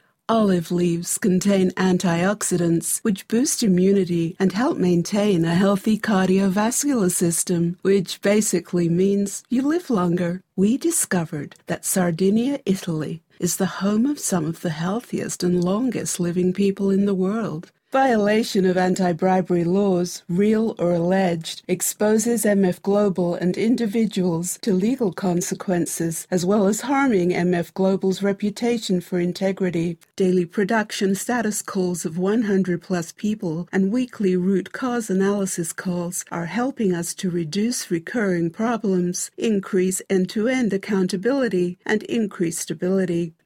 Voiceover actor working in British and Global Mid-Atlantic English from Los Angeles
Sprechprobe: Industrie (Muttersprache):
Warm, sophisticated and believable. Alto timbre.